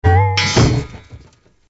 SA_canned_impact_only.ogg